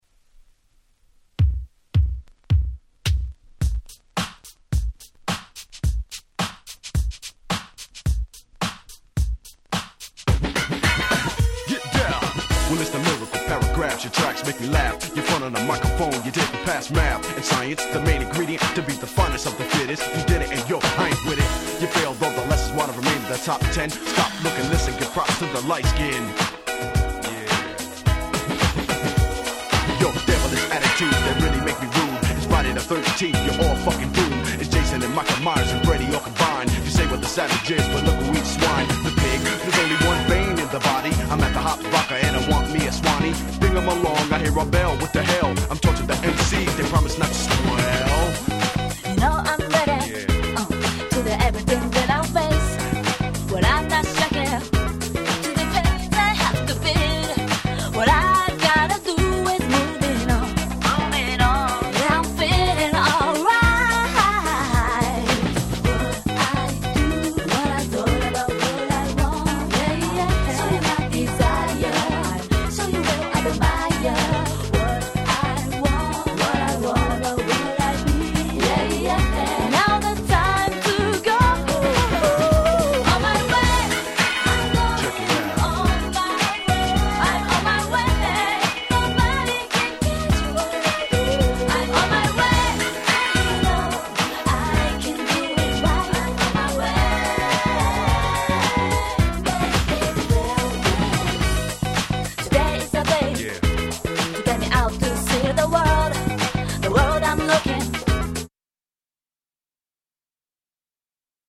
当時『キャッチーR&B』ブームの真っ只中だっただけに内容も英詩＆PopなキャッチーR&Bトラックで言うこと無し！！